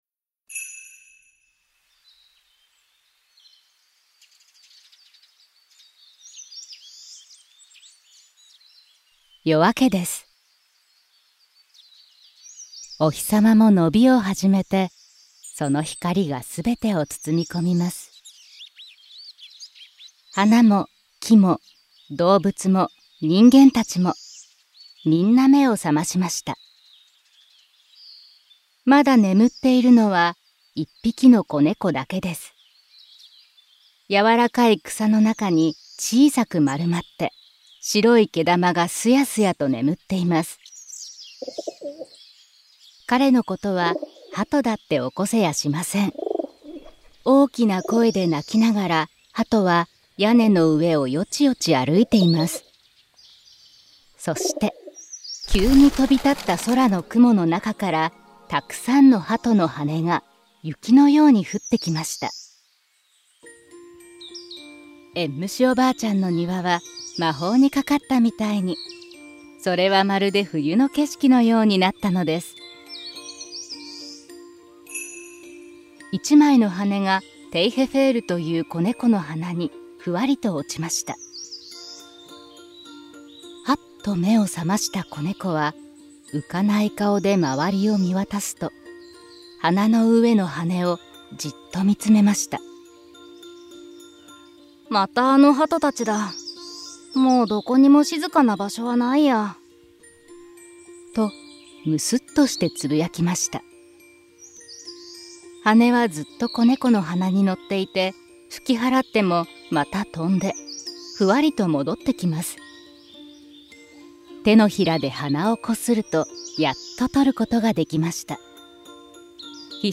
[オーディオブック] テイフェヘールとほこりねこ
聴きながら、鈴の音に合わせてめくりながら、絵本の世界を楽しめます。